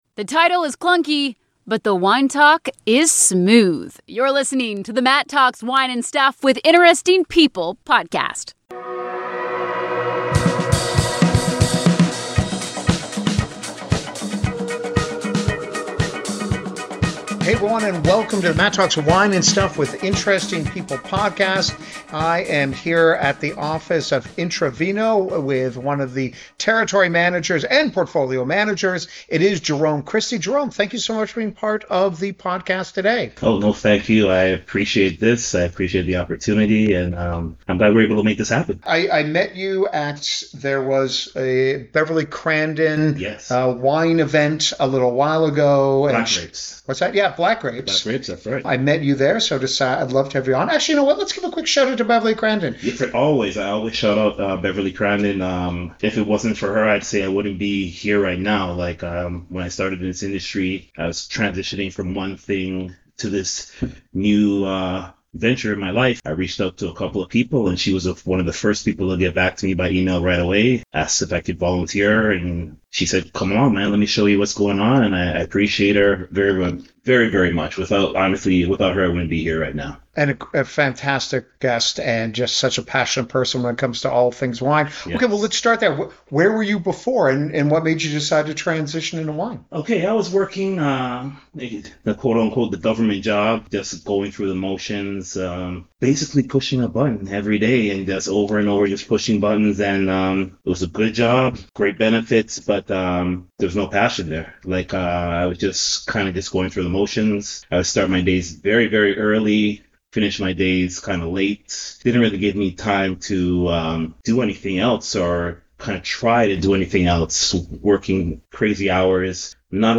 This is a great interview for people looking to get into the wine business cause